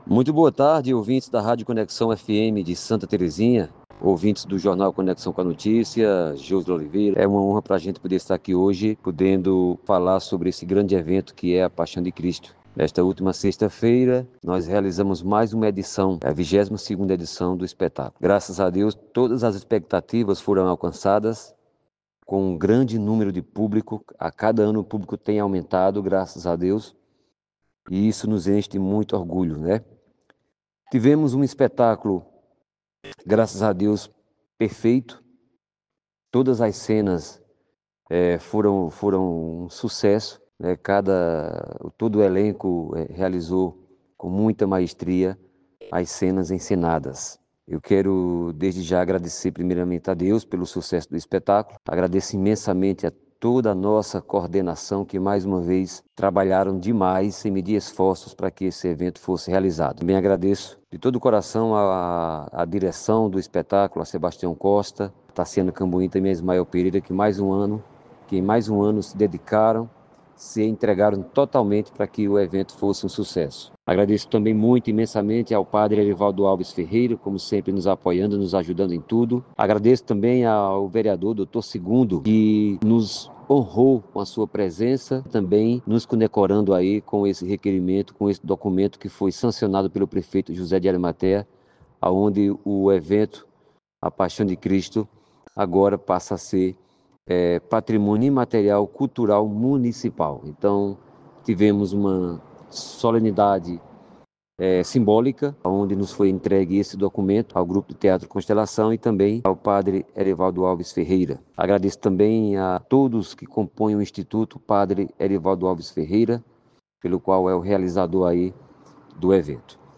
transmitido na Rádio Conexão (104,9 FM) e avaliou o espetáculo.